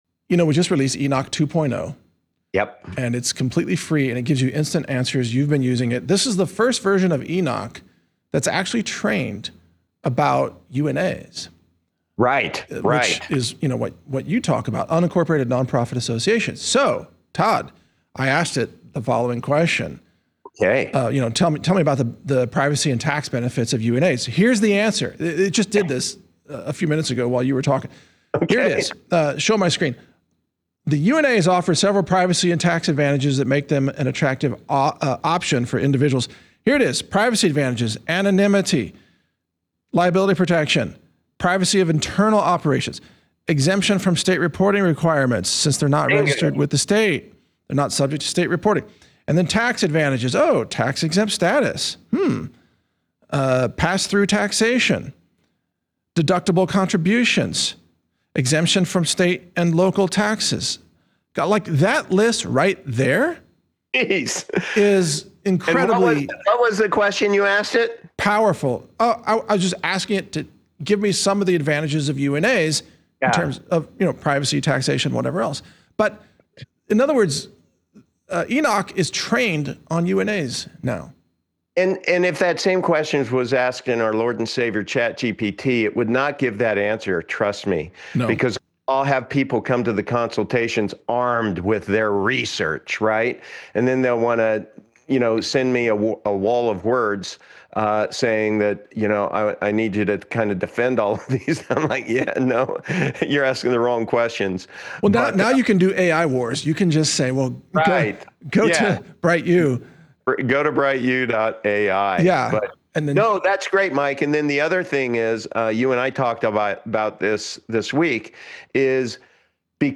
Enoch 2.0 demonstration